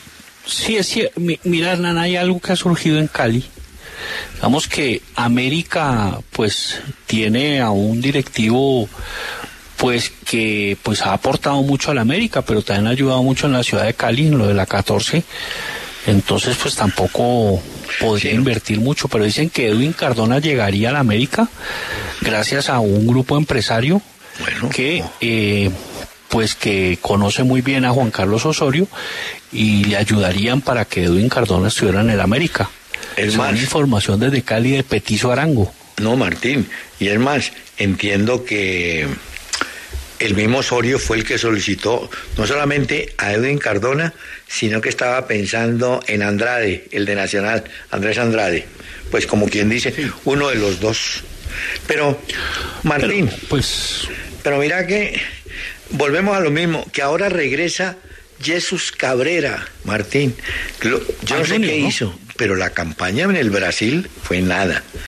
Escuche a continuación el análisis de Hernán Peláez y Martín De Francisco: